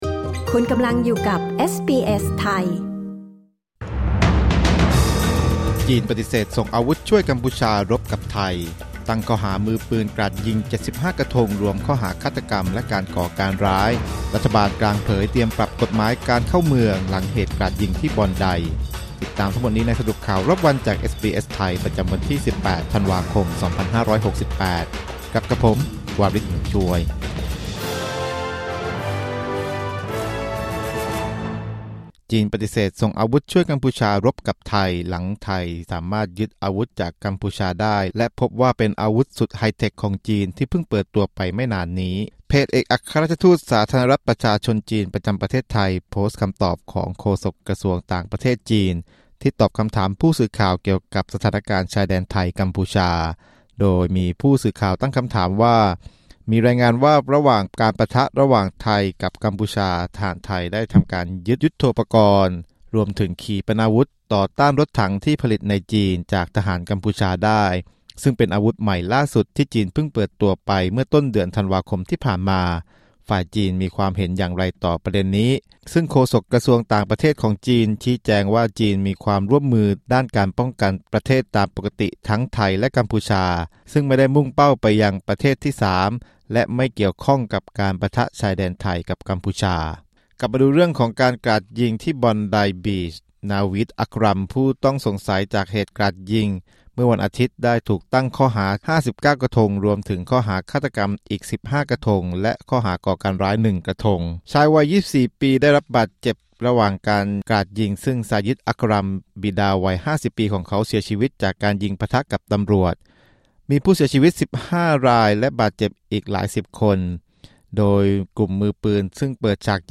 สรุปข่าวรอบวัน 18 ธันวาคม 2568